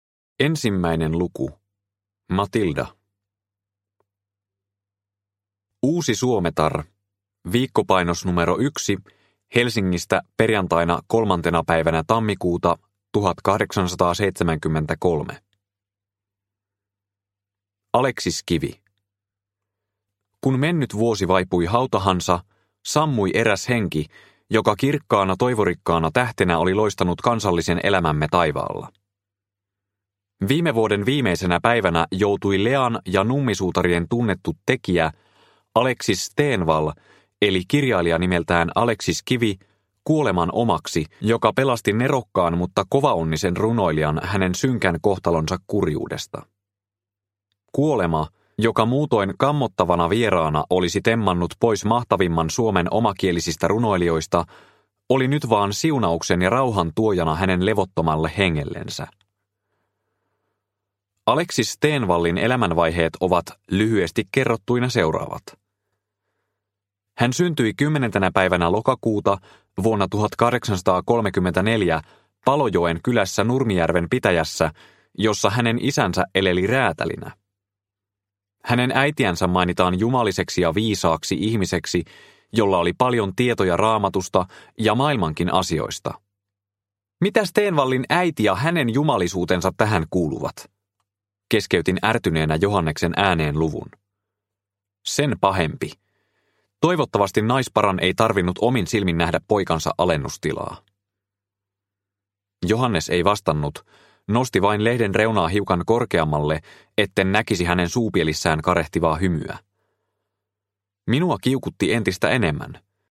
Stenvallin tapaus – Ljudbok – Laddas ner